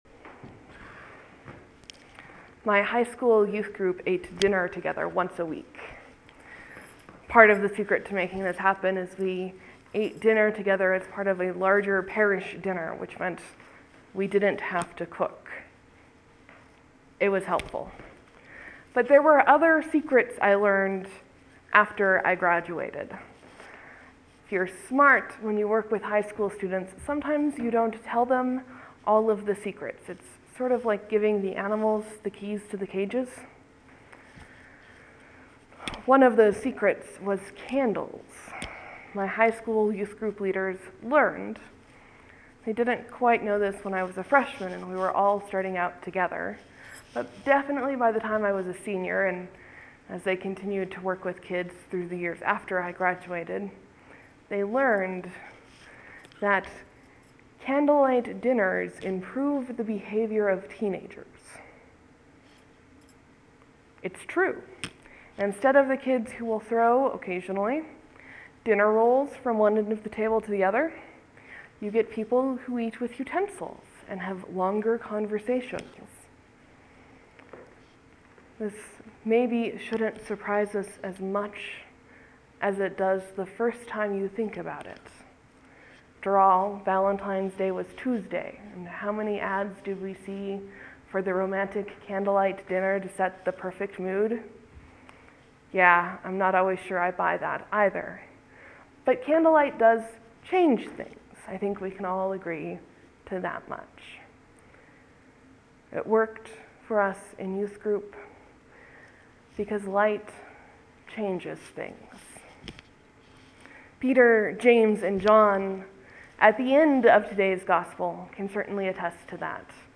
(There will be a few moments of silence before the sermon begins. Thanks for your patience.)